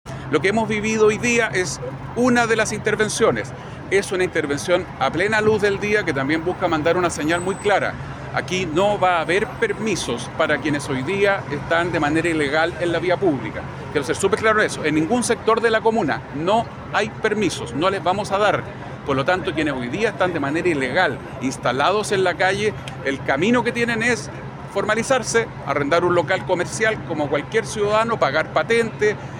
El alcalde de Santiago, Mario Desbordes, fue enfático al señalar que en su comuna no se otorgarán permisos para el comercio ilegal. Aclaró que quienes deseen operar deberán arrendar un local y hacerlo de manera formal.